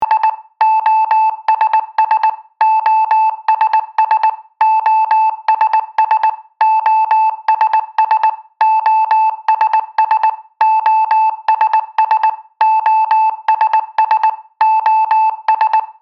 SOS este un cod Morse simplu și ușor de transmis și recepționat. Este format din trei puncte, trei linii și din nou trei puncte (· · · – – – · · ·).